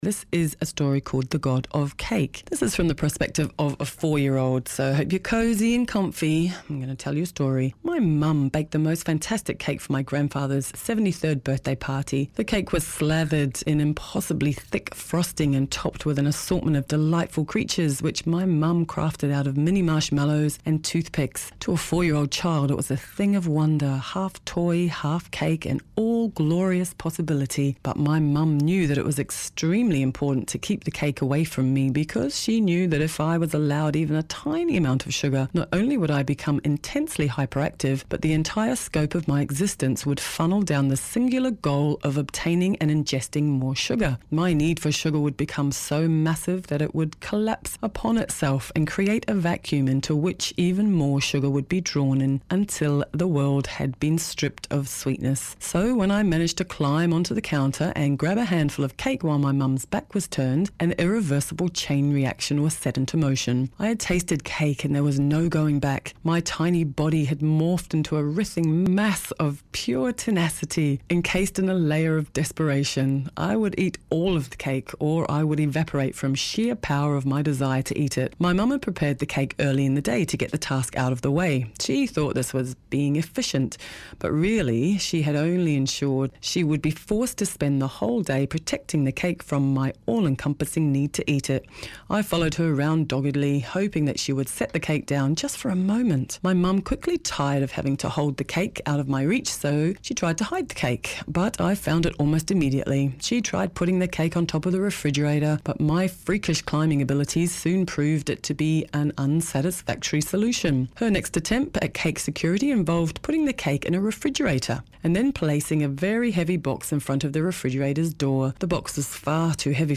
Here’s the wonderful birthday cake story i found and read out by Allie Brosh.
God of Cake reading – audio
God-of-Cake-reading2.mp3